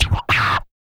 Index of /90_sSampleCDs/ILIO - Vocal Planet VOL-3 - Jazz & FX/Partition H/1 MALE PERC